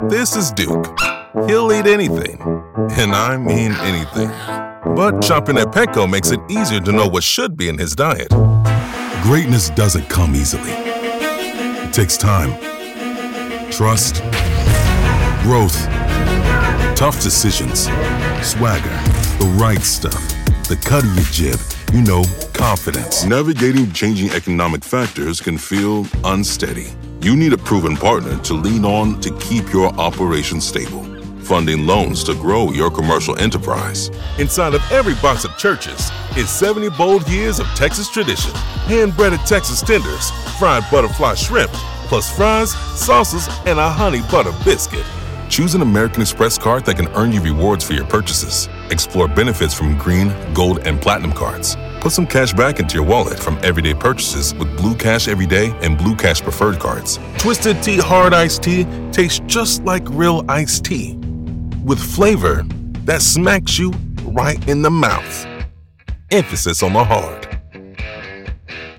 Deep Voice, Powerful, Epic
Commercial